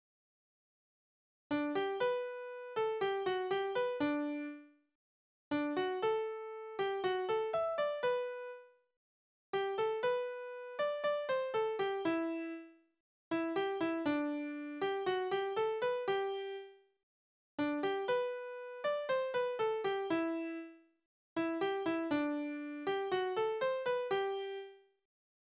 Balladen: Er findet seine Liebste als Gärtnersfrau wieder
Tonart: G-Dur
Taktart: 4/4
Tonumfang: Oktave
Besetzung: vokal